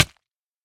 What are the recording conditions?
should be correct audio levels.